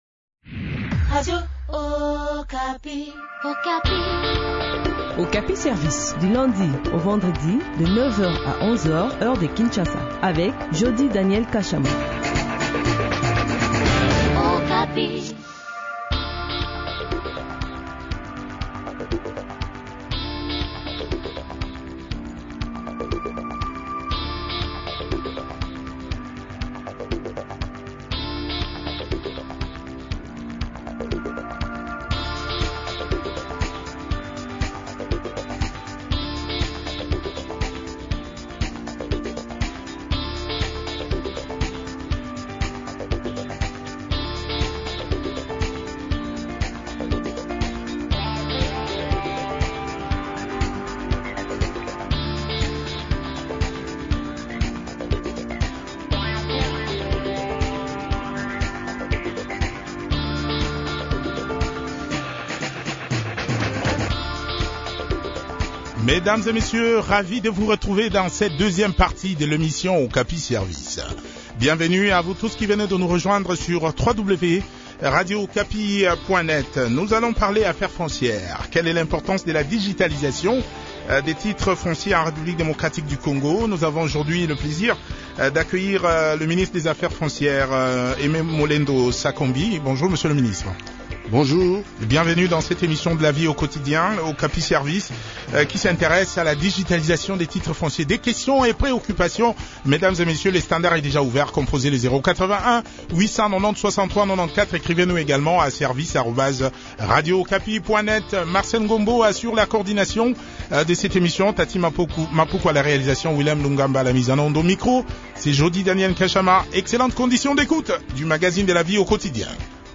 discute de ce sujet avec Molendo Sakombi, ministre des Affaires foncières.